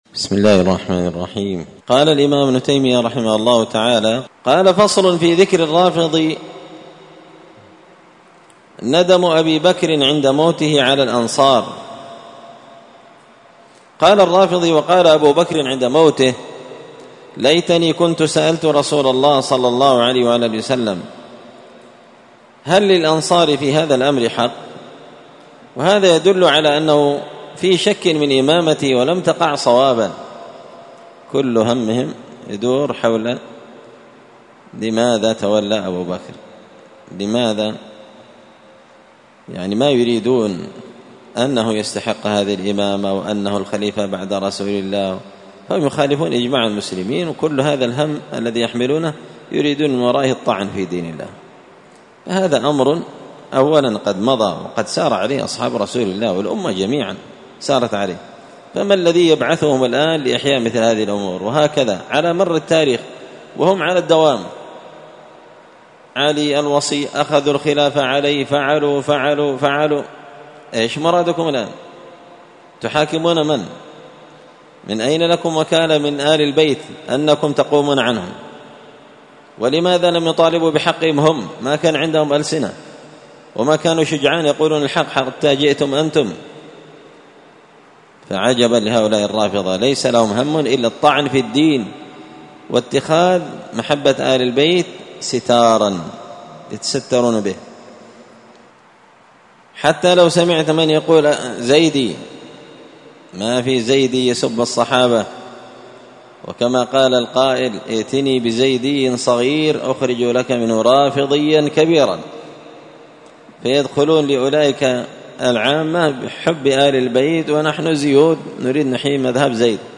الأربعاء 25 ذو القعدة 1444 هــــ | الدروس، دروس الردود، مختصر منهاج السنة النبوية لشيخ الإسلام ابن تيمية | شارك بتعليقك | 10 المشاهدات